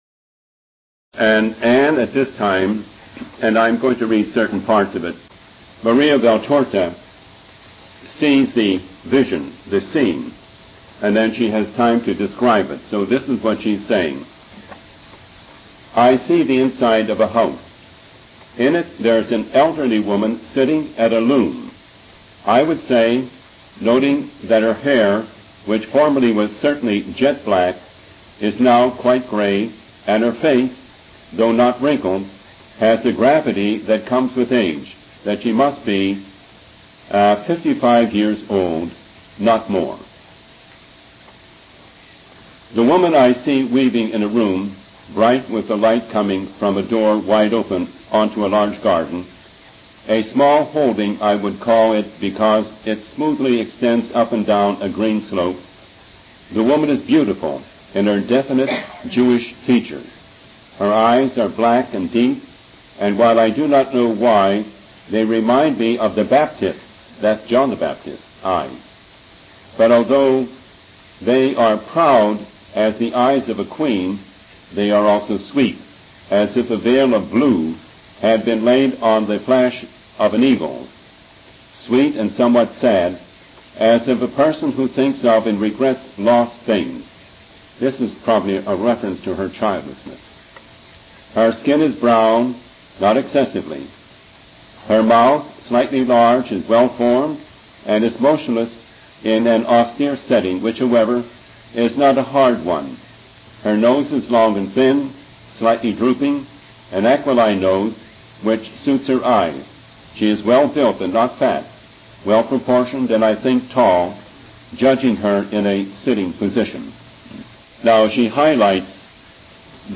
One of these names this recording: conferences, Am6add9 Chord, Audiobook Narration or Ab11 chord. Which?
conferences